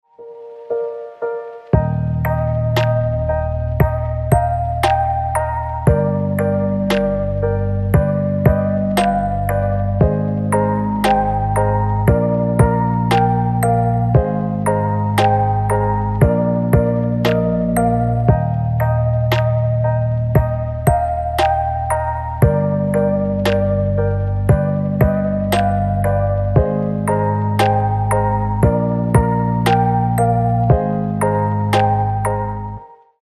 • Качество: 320, Stereo
красивые
спокойные
без слов
пианино
медленные
расслабляющие
Chill
колыбельные
Расслабляющая колыбельная